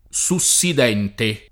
sussidente [ S u SS id $ nte ]